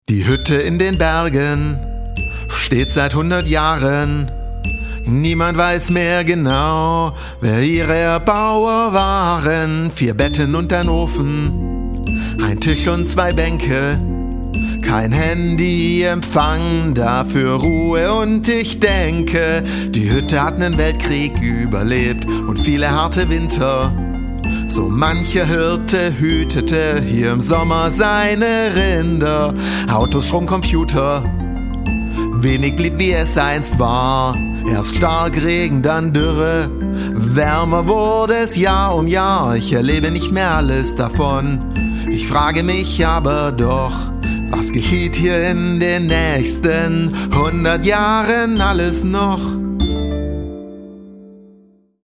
Eigentlich hatte ich vor, dieses Lied langsamer zu spielen.